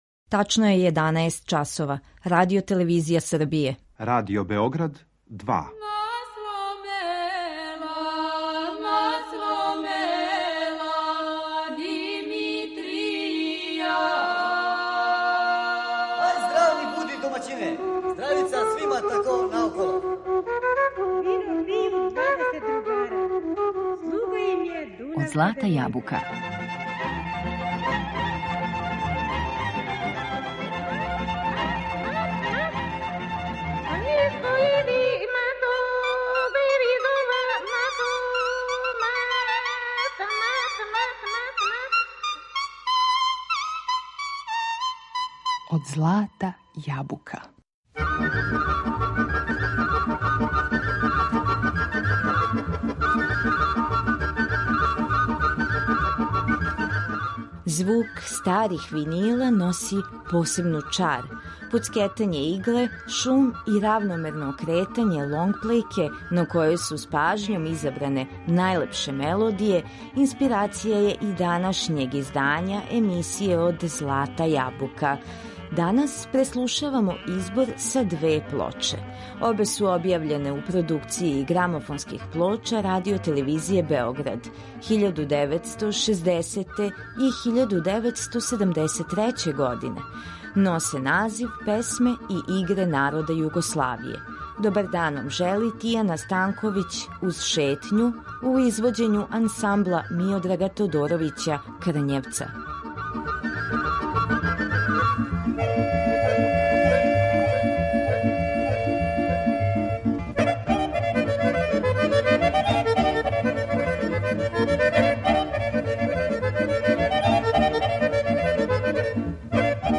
У циклусу су уврштени најбољи вокални солисти уз пратњу Народног оркестра.